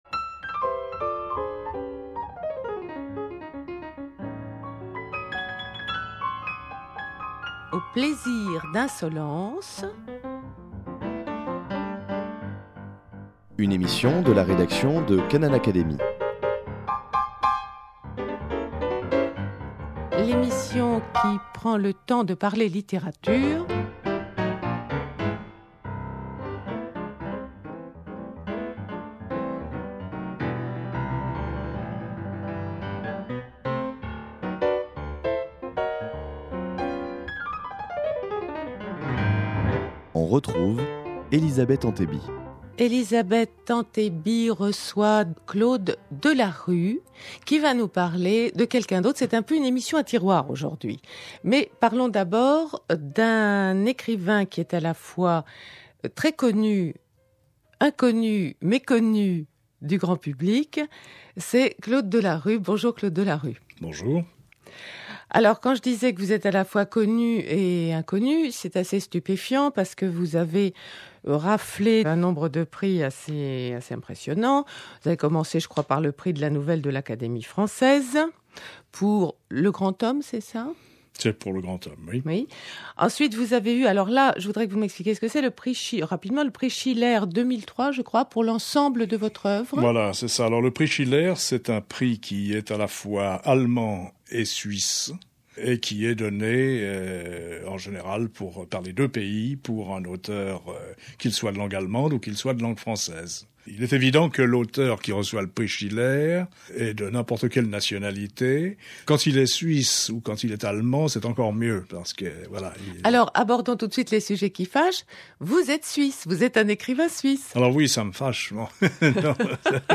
Dans cette émission littéraire « Au plaisir d’insolence »